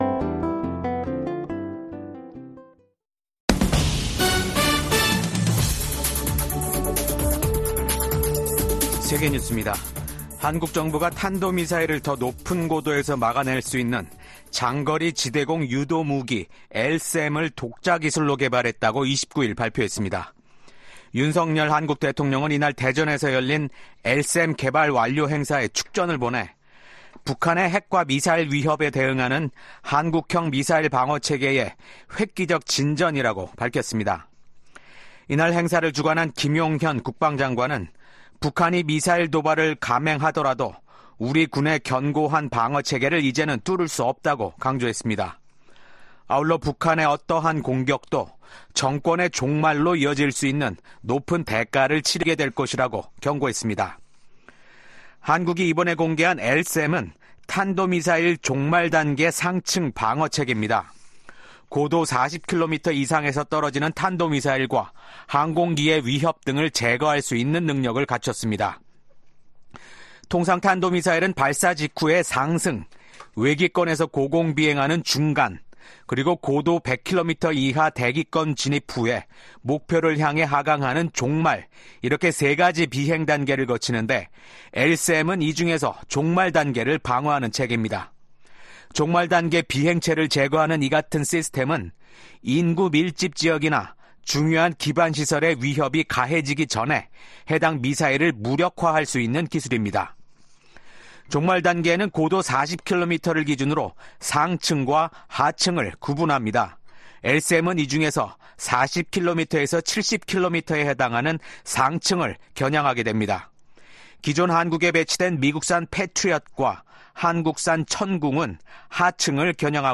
VOA 한국어 아침 뉴스 프로그램 '워싱턴 뉴스 광장'입니다. 우크라이나와의 전쟁이 치열해지는 가운데 러시아의 안드레이 벨로우소프 국방장관이 북한을 공식 방문했습니다. 미국 국무부가 북한 국적자를 포함한 개인 3명과 러시아, 중국 회사에 제재를 단행했습니다. 북한과 중국이 강제 북송된 탈북민을 강제 노동에 동원해 경제적 이익을 공유하고 있다는 연구 결과가 나왔습니다.